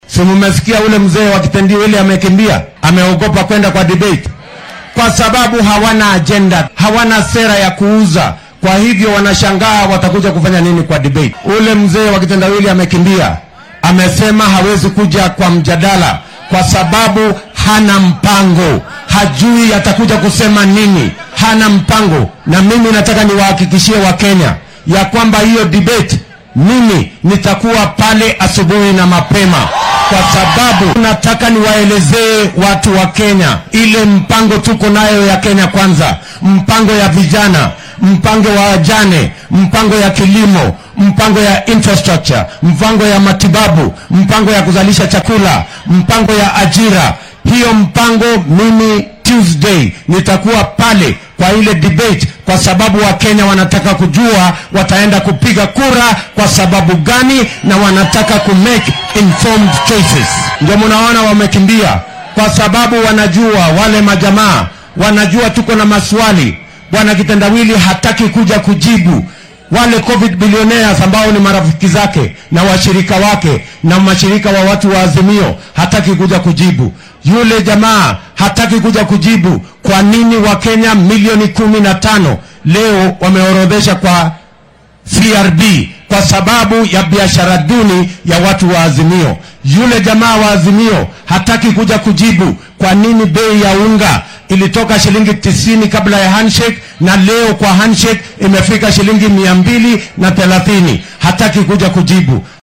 Waxaa uu sheegay in Raila uu qaadacay ka qaybgalka doodda maadaama sida uu hadalka u dhigay uunan hayn qorsho uu kenyaanka u faahfaahin karo. Xilli uu dadweynaha kula hadlay fagaaraha Erera ee ismaamulka Kisii ayuu William Ruto carrabka ku adkeeyay in Raila uu ka cabsi qabo inuu su’aalo adag oo musuqmaasuqa uu ka mid yahay uu weydiiyo.